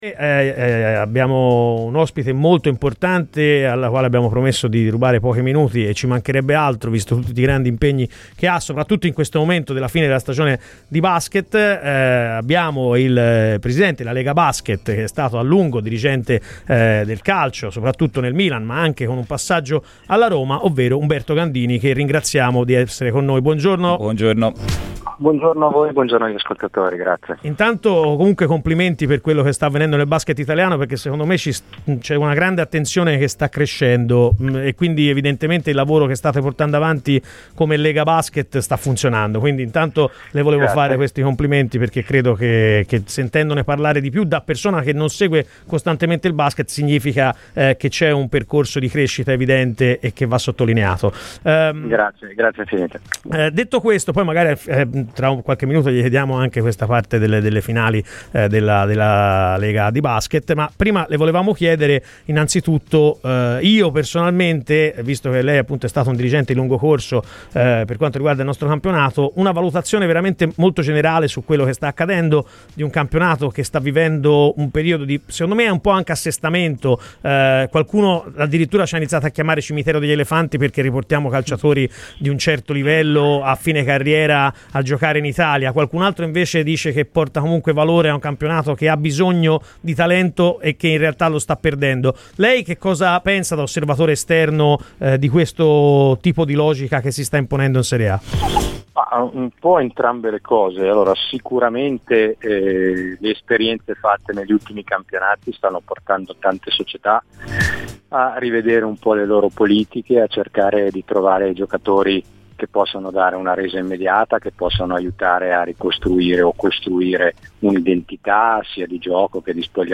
intervenuto nella mattinata di Radio FirenzeViola